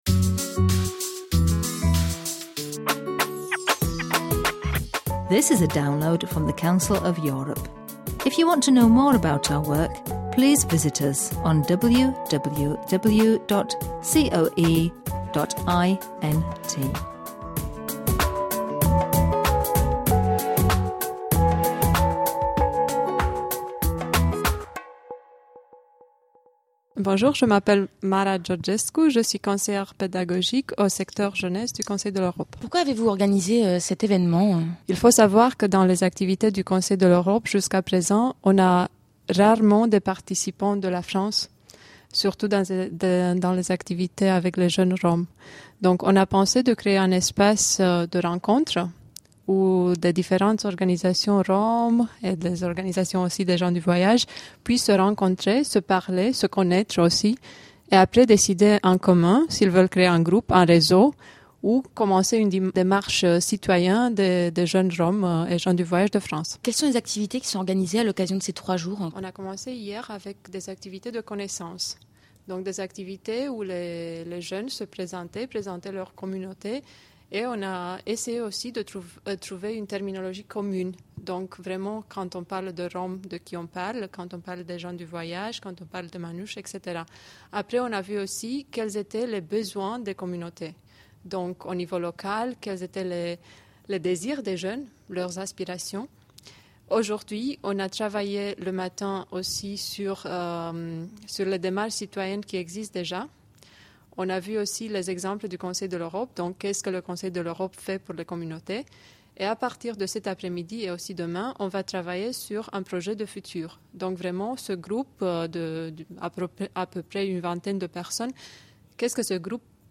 Ecoutez les histoires des participants à la première rencontre de jeunes Roms et gens du Voyage de France: